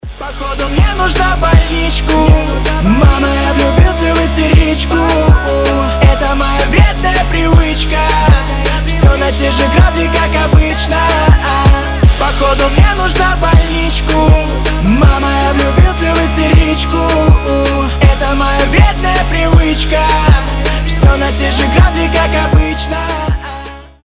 громкие